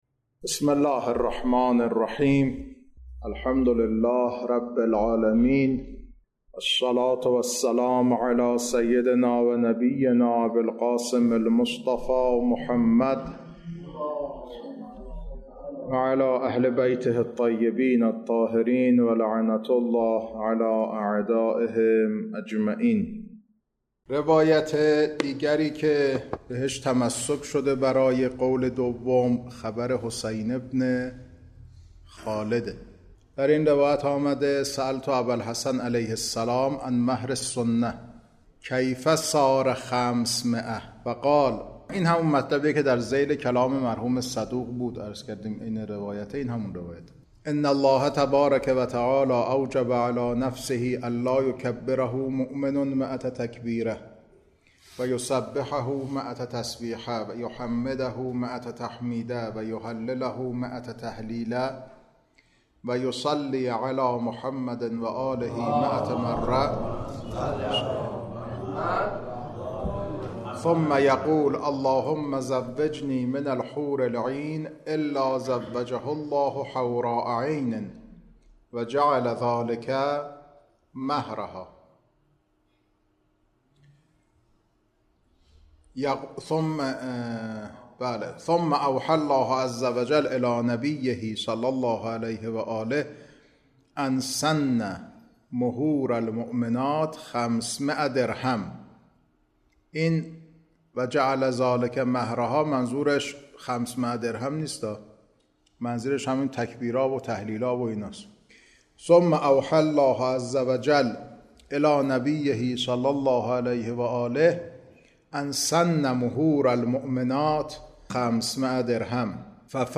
کلاس‌ها خارج فقه، بحث نکاح